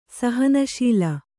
♪ sahana śila